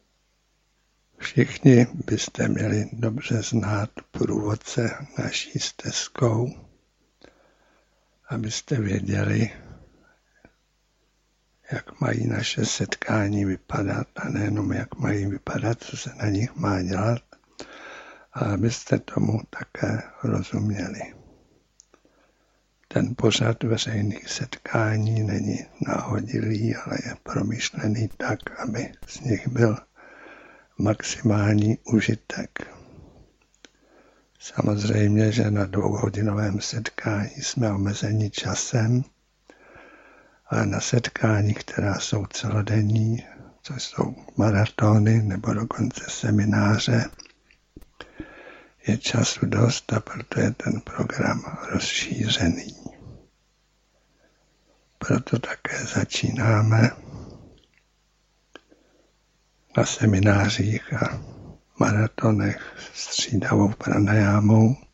Přímý záznam z meditačního maratonu v klubovně Duchovní Srdce ze soboty 10. listopadu 2007. Obsahuje průvodní slovo ke sledu meditací, které provádíme na celodenních setkáních spolu s vysvětlením, jak je provádíme, proč je provádíme a jaké výsledky můžeme od jednotlivých postupů očekávat.
Nejedná se o studiovou nahrávku.